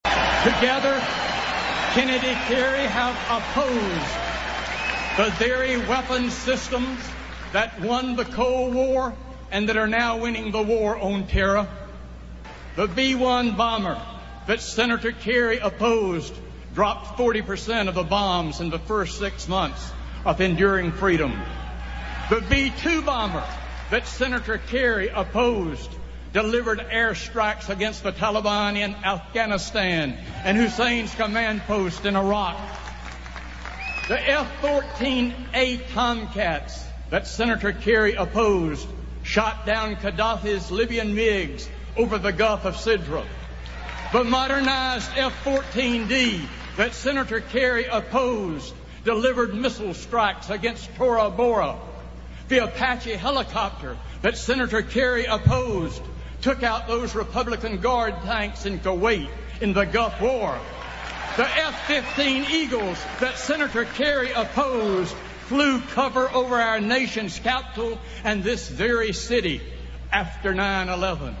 Tags: Enumeratio Parsing Speeches Jerry Falwell Sienfeld Junior Mint